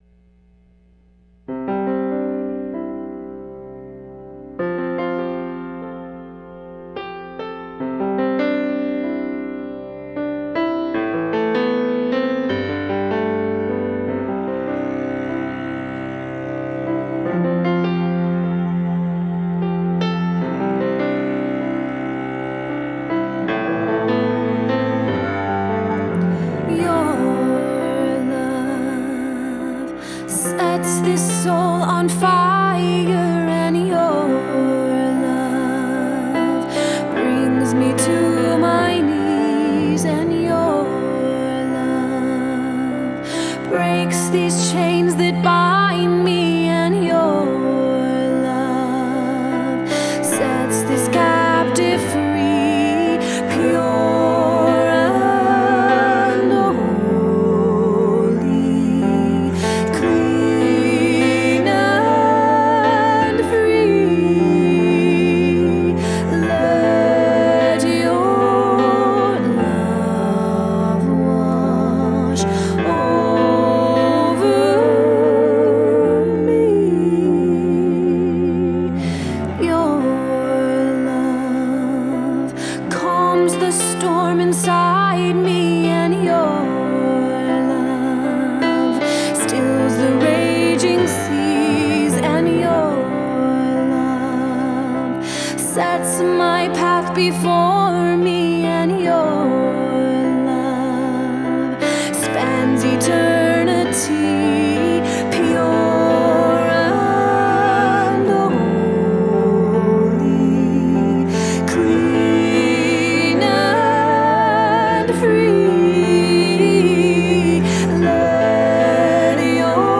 Contemporary Christian music